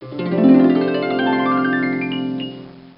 harp.wav